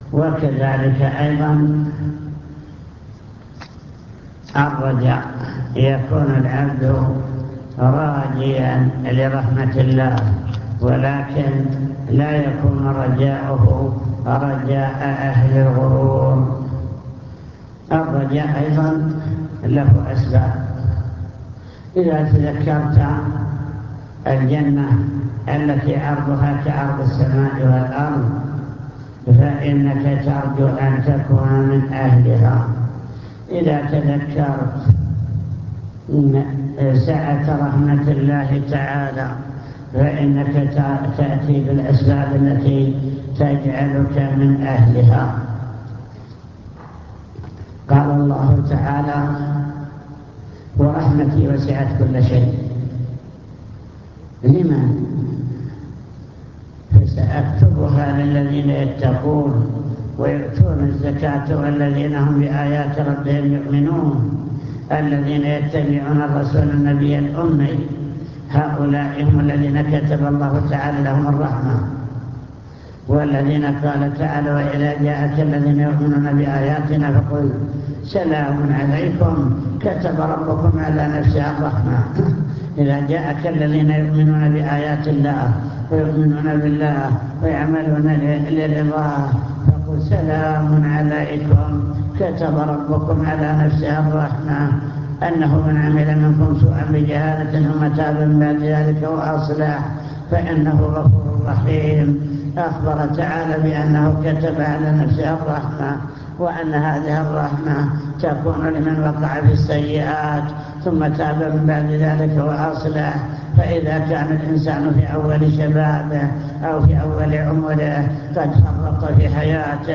المكتبة الصوتية  تسجيلات - لقاءات  كلمة في معهد سلاح المدرعات